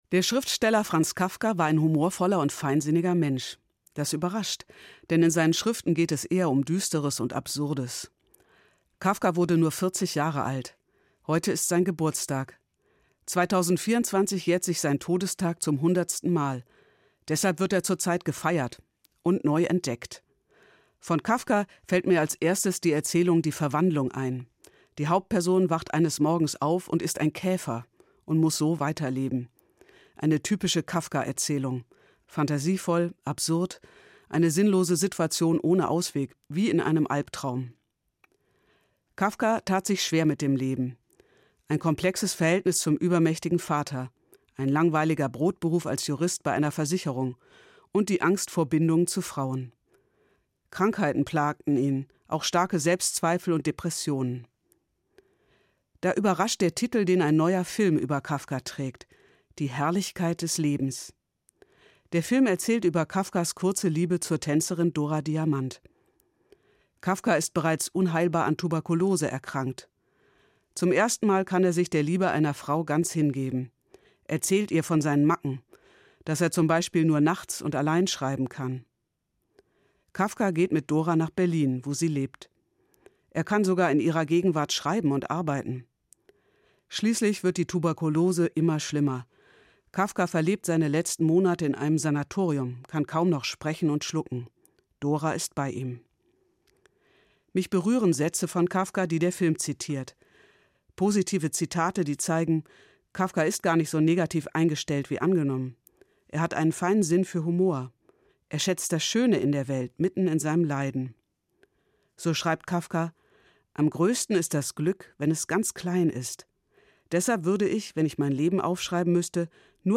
Evangelische Pfarrerin, Frankfurt